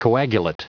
Prononciation du mot coagulate en anglais (fichier audio)
coagulate.wav